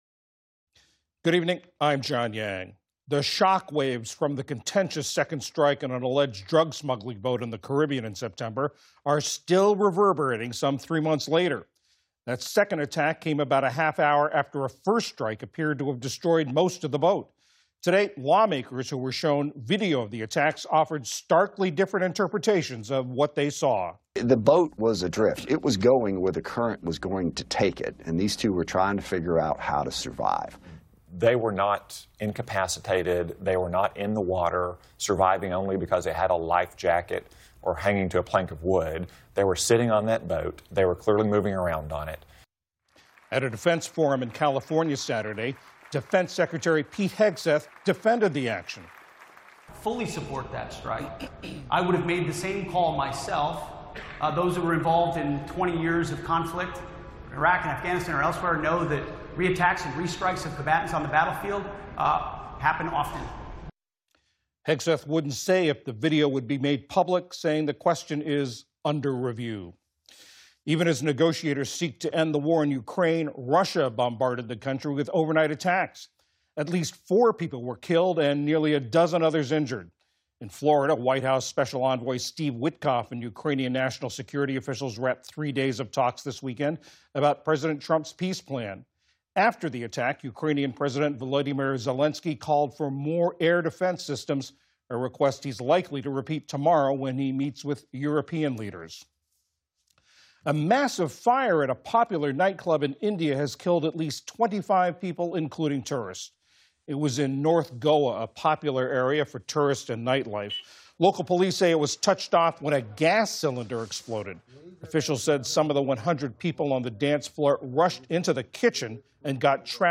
newswrap-5.mp3